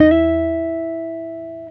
Guitar Slid Octave 17-E3.wav